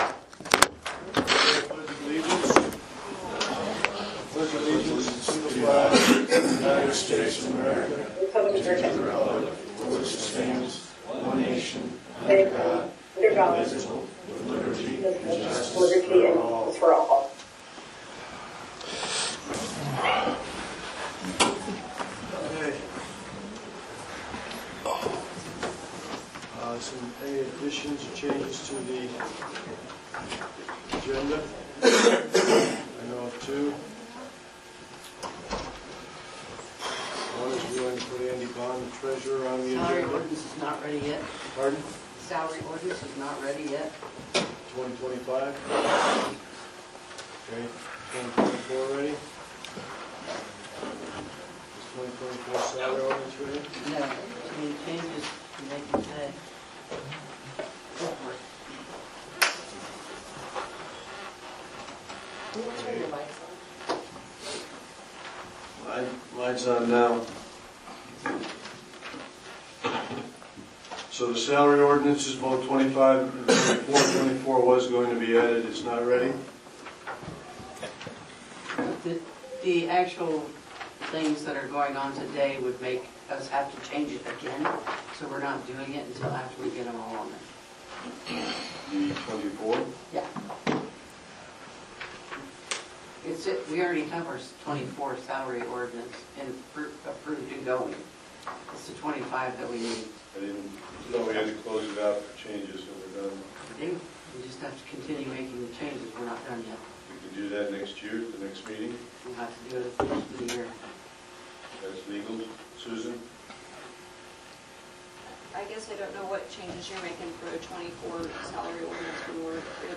County Council Special Meeting Notes, Dec 30, 2024, A Way Ahead